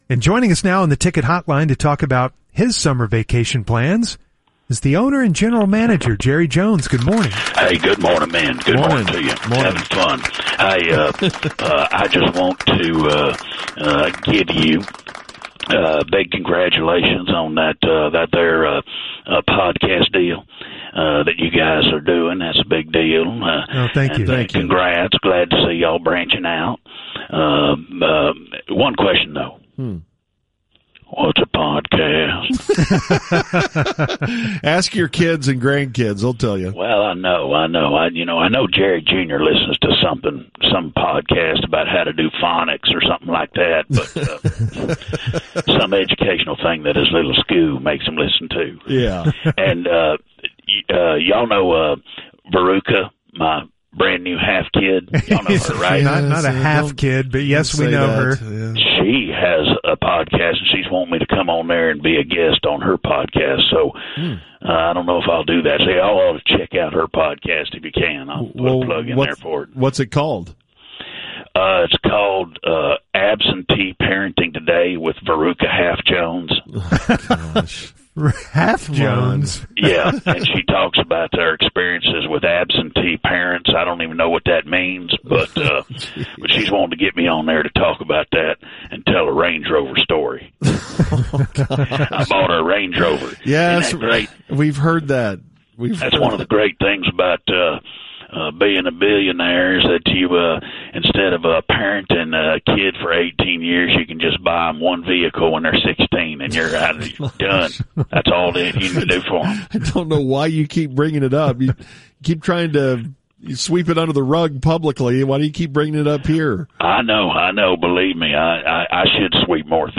Fake Jerry Jones – The Musers 6.20.2025